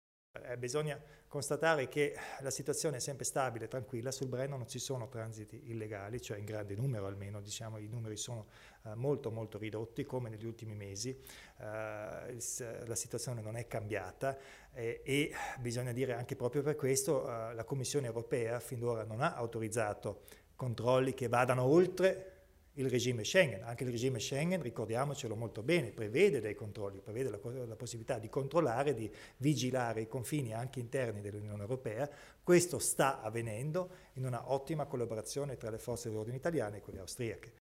Il Presidente Kompatscher prende posizione sull'aumento dei controlli al Brennero